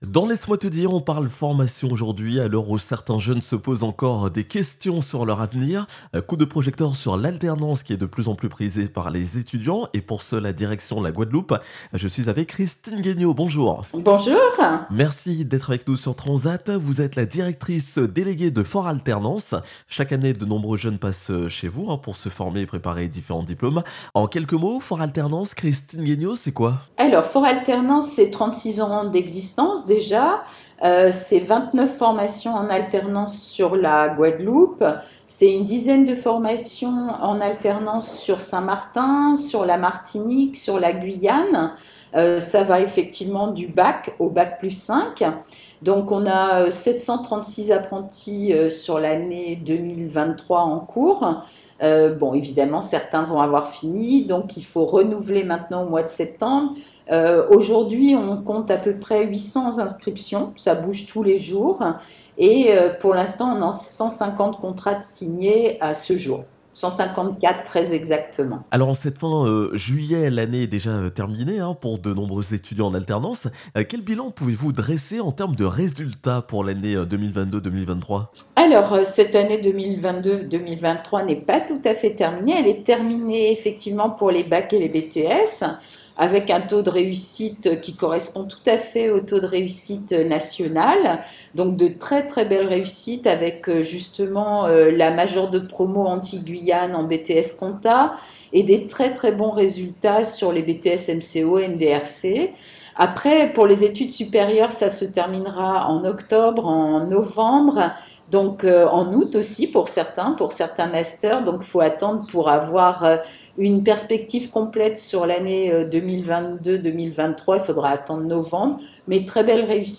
Invitée du jour